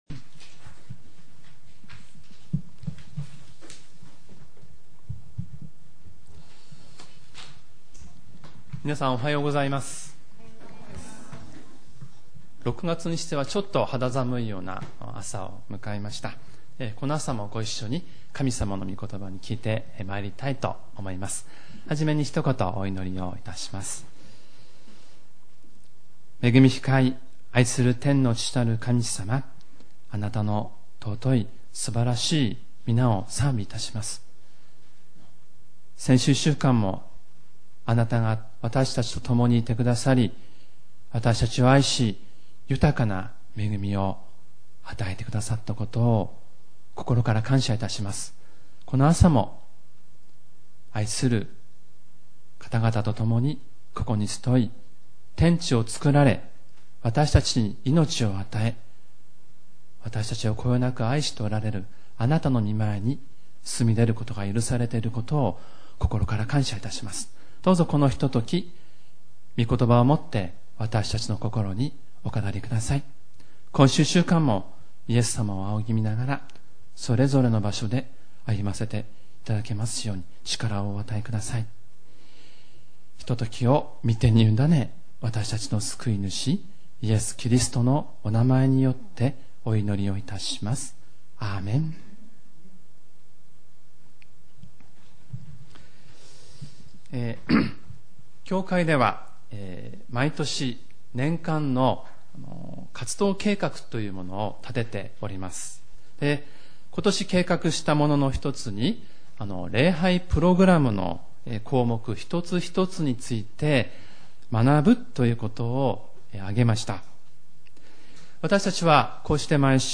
●主日礼拝メッセージ（MP３ファイル、赤文字をクリックするとメッセージが聞けます）